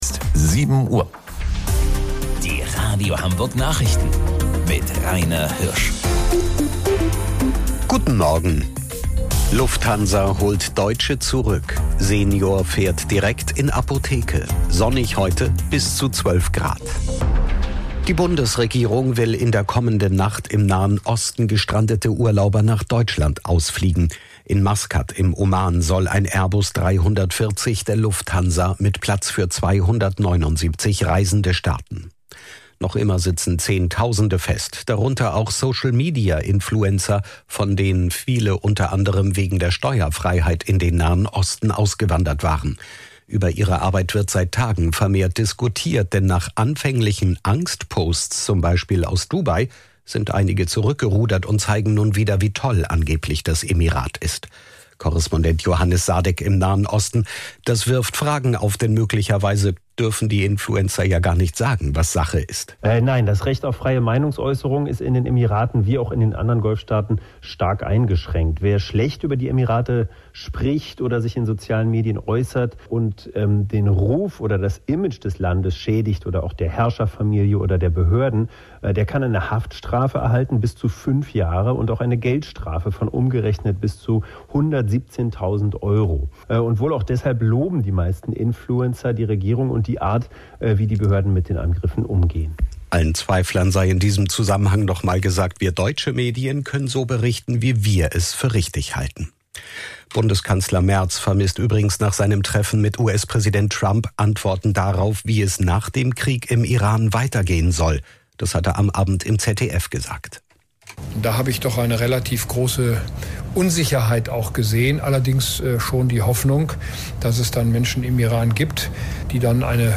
Radio Hamburg Nachrichten vom 04.03.2026 um 07 Uhr